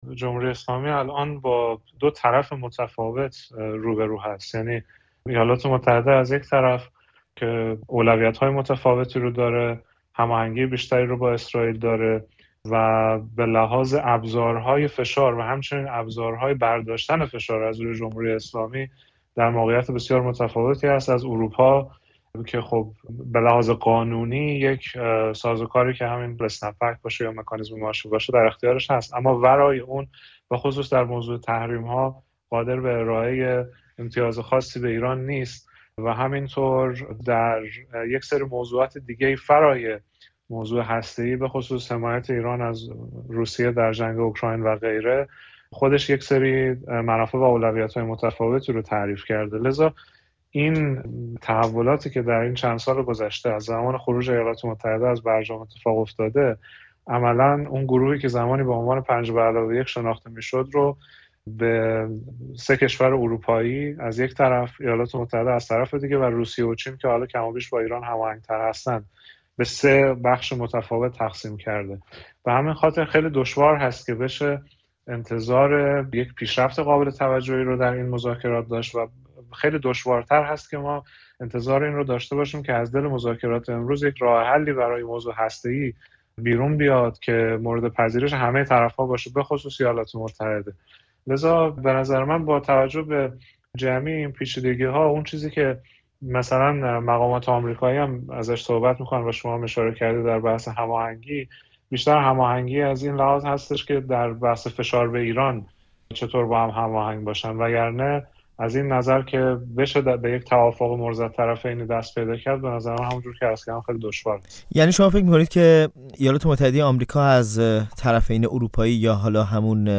در گفت‌وگو با رادیوفردا